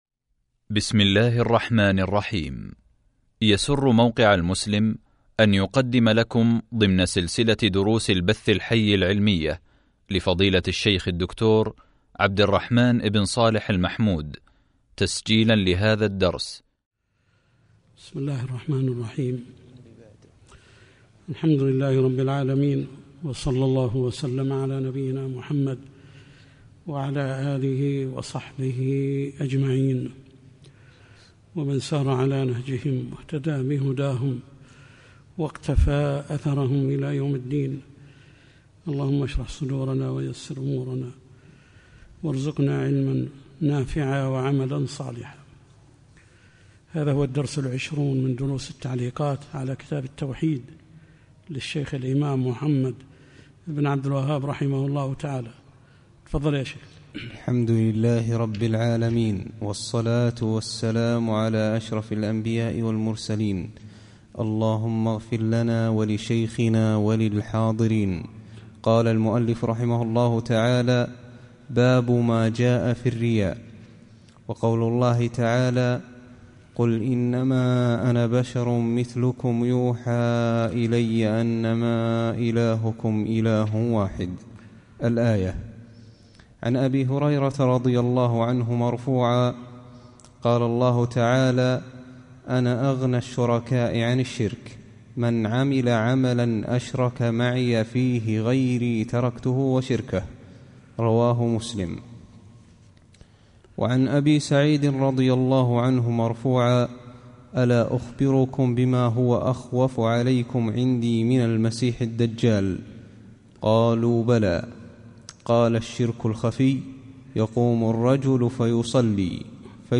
شرح كتاب التوحيد | الدرس 20 | موقع المسلم